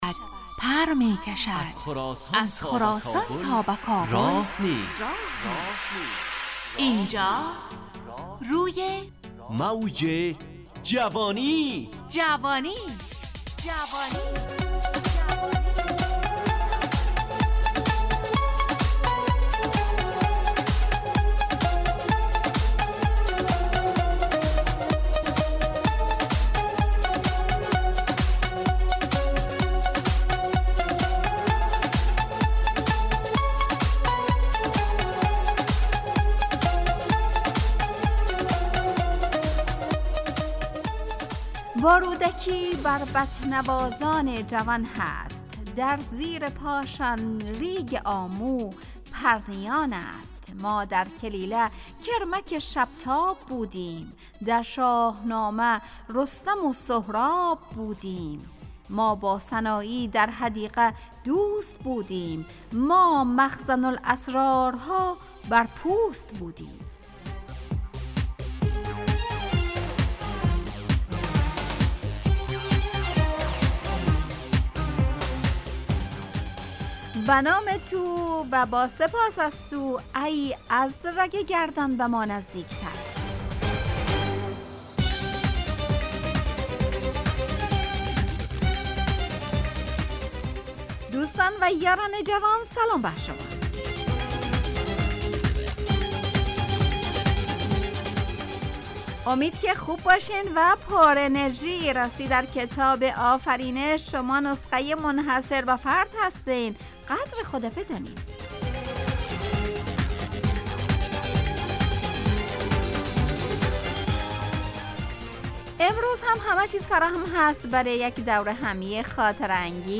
همراه با ترانه و موسیقی مدت برنامه 55 دقیقه . بحث محوری این هفته (اگر کتاب نخوانیم ...)